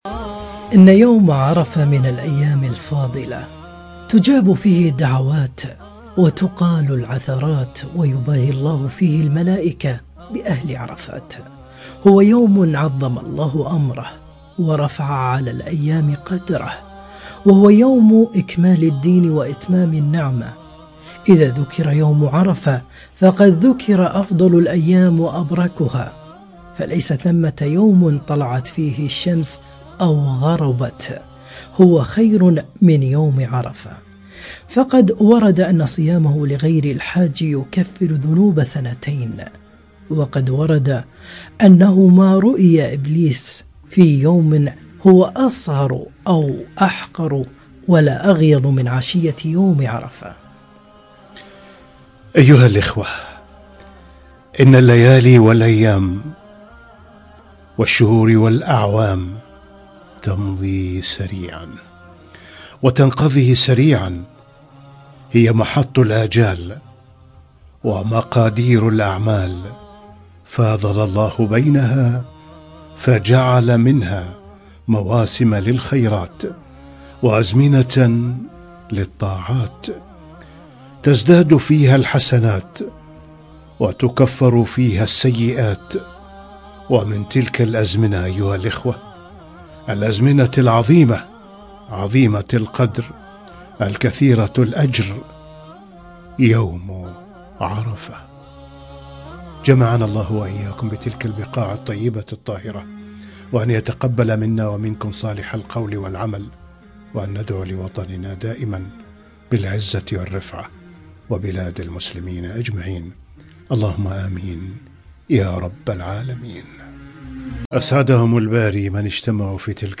يوم عرفة وفضله - لقاء إذاعي في واحة المستمعين